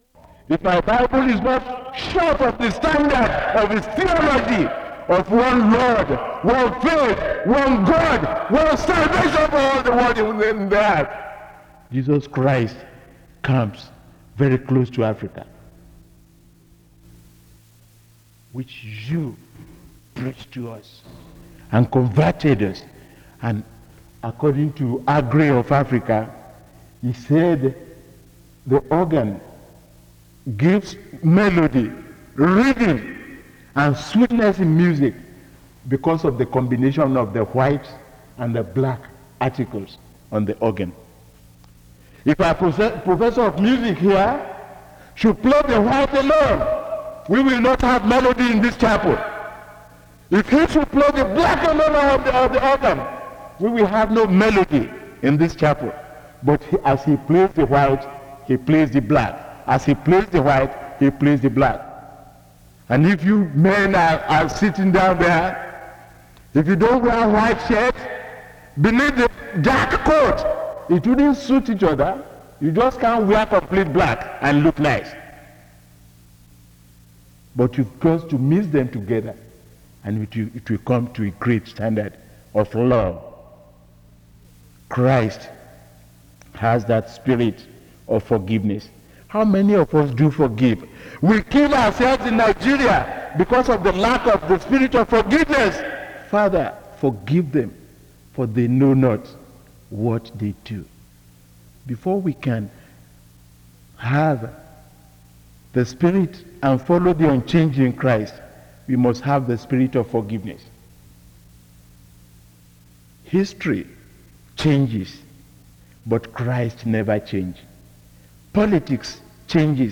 The service begins with an introduction to the speaker from 0:00-7:09.
• Wake Forest (N.C.)